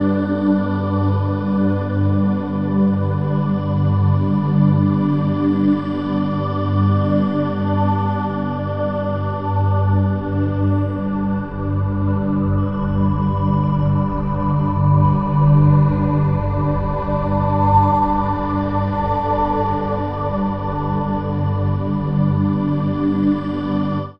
36ae01pad-gM.wav